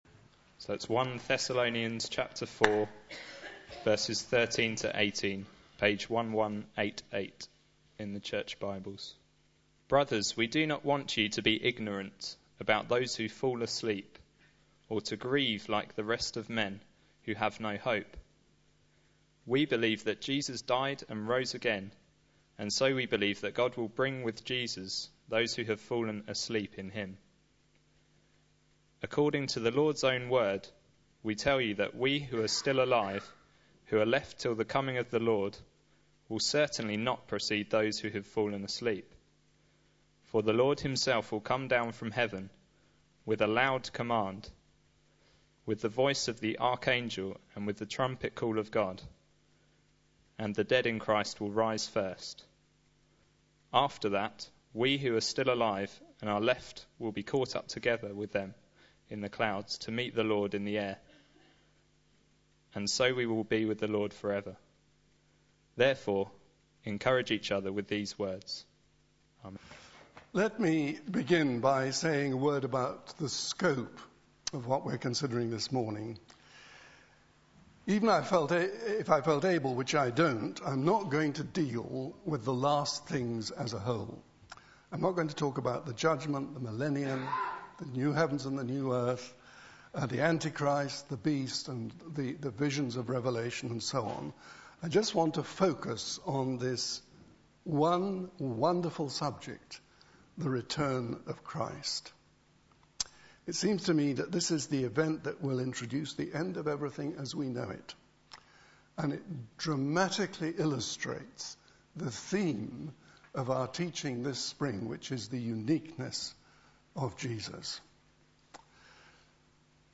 Sunday Service
The Return of Christ Sermon